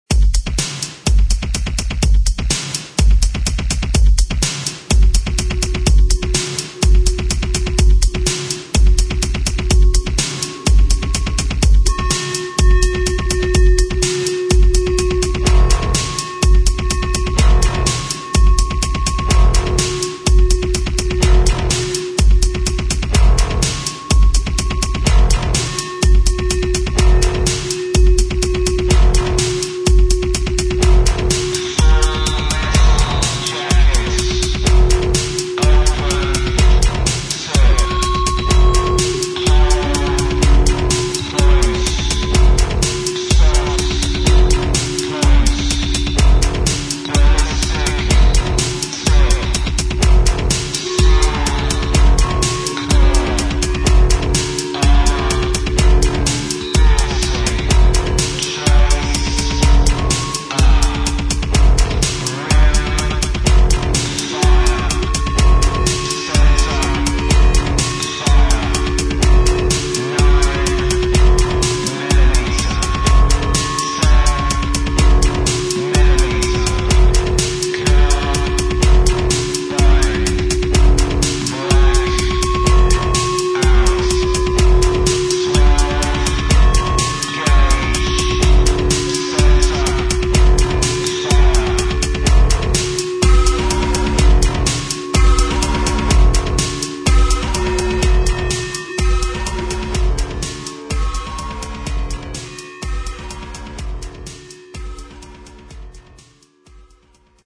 [ TECHNO / INDUSTRIAL ]